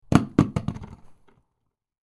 Apples Dropping On The Floor Sound Effect
Description: Apples dropping on the floor sound effect. A crisp sound effect of several apples hitting a hard surface.
Apples-dropping-on-the-floor-sound-effect.mp3